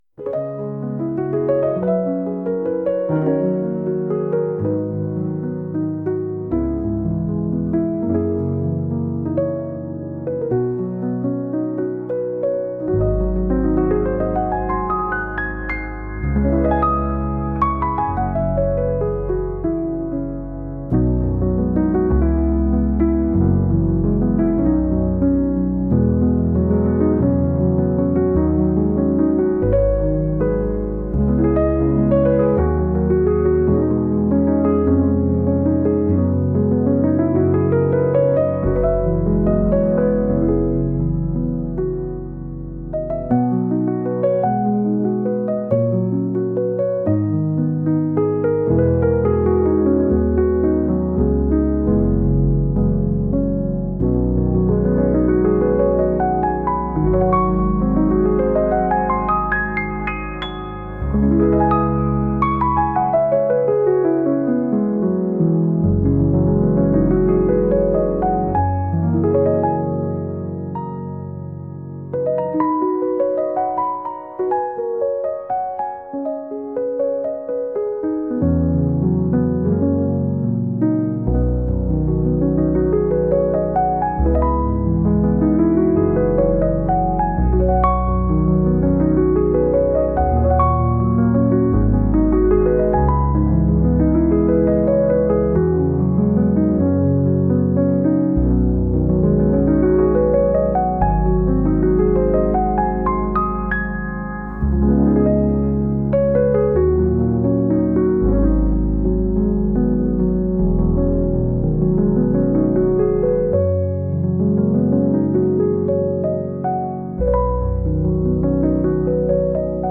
はかなげなピアノ曲です。